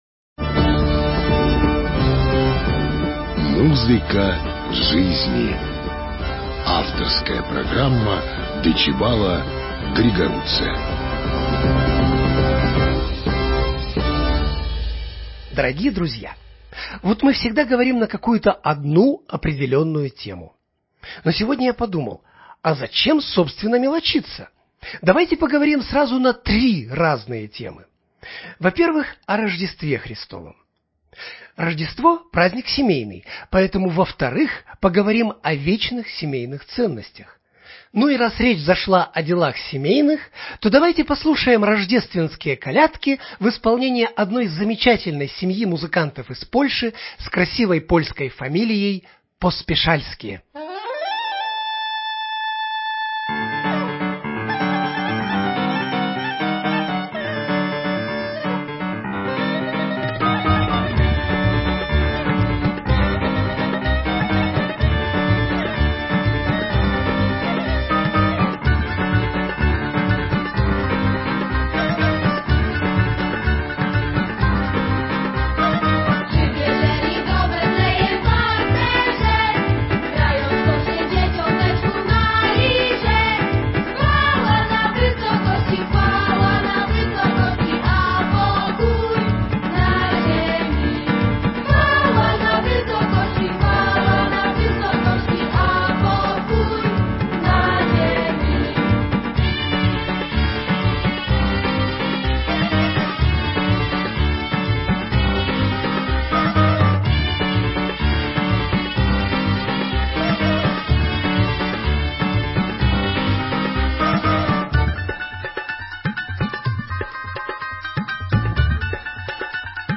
Сегодня мы повторяем рождественскую передачу, которая впервые прозвучала в нашем эфире 23 декабря 2019 года.